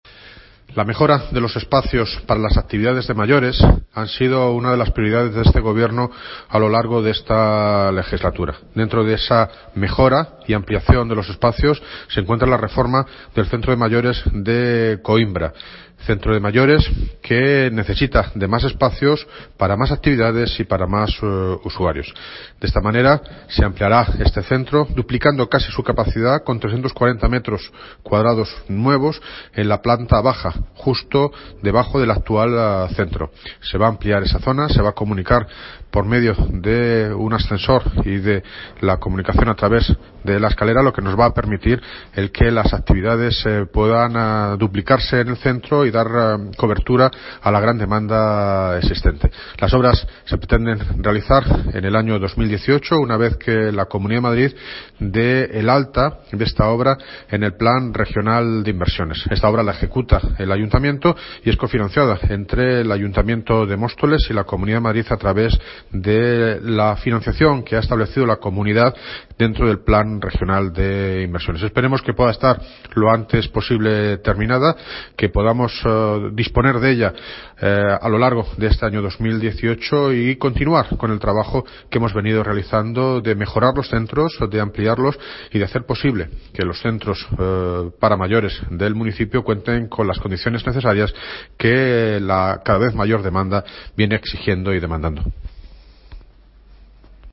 Audio - David Lucas (Alcalde de Móstoles) Sobre ampliación Centro Mayores Coimbra